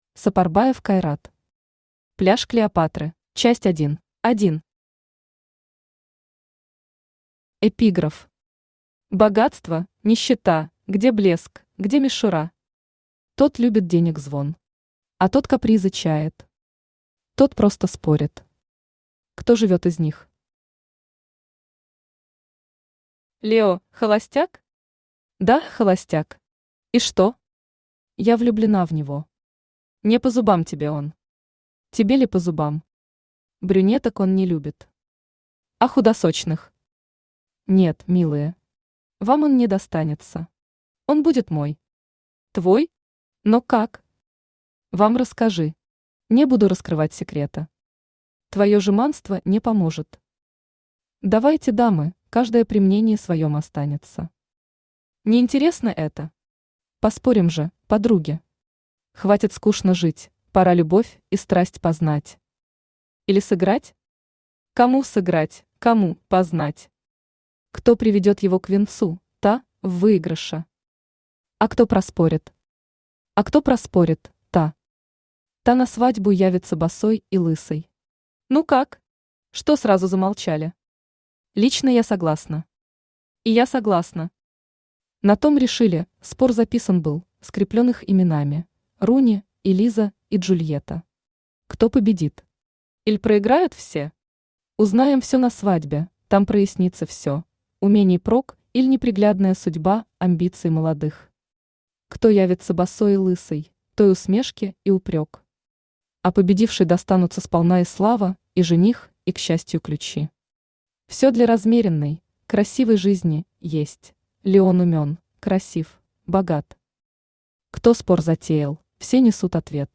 Аудиокнига Пляж Клеопатры | Библиотека аудиокниг
Aудиокнига Пляж Клеопатры Автор Сапарбаев Кайрат Читает аудиокнигу Авточтец ЛитРес.